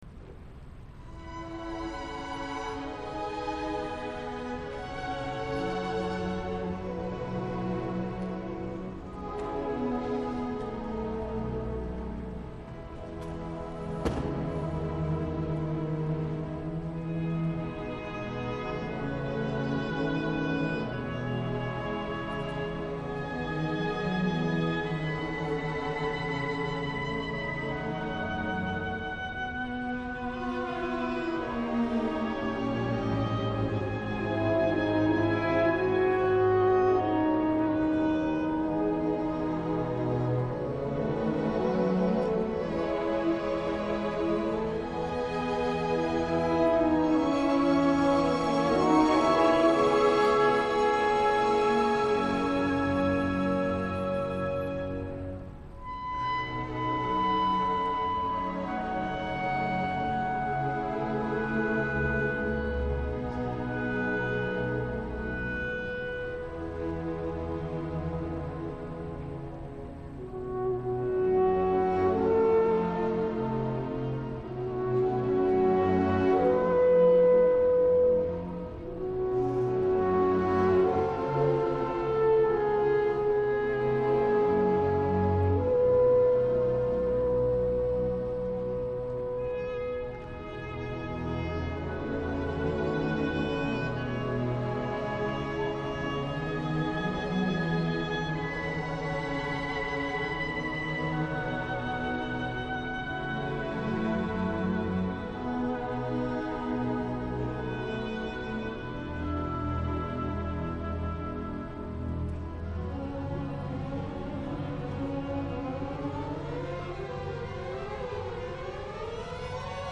Leroy J. Robertson:  Oratorio from the Book of Mormon
Andante